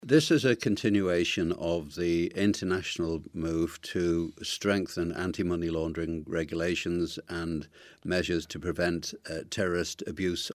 Chief Minister Allan Bell says it's important: